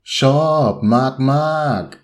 ∧ shoorb ∧ maag ∧ maag